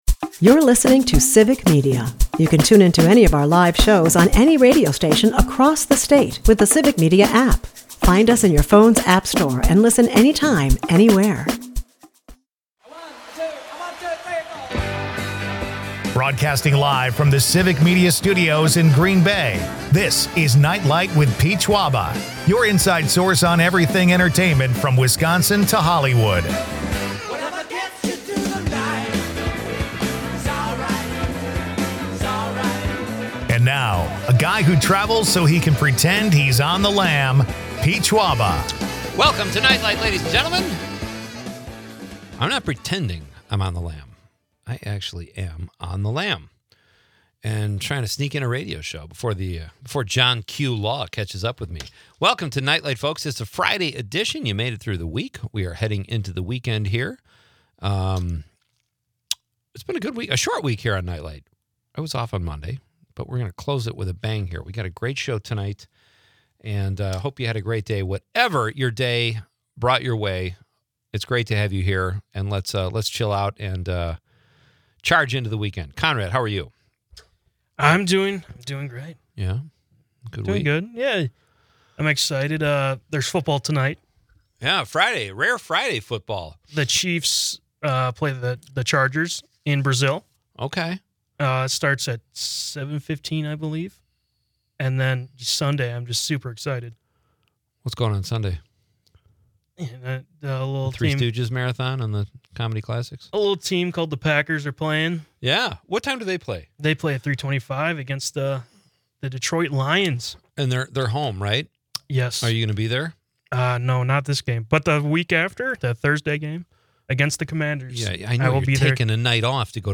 Broadcasting live from Green Bay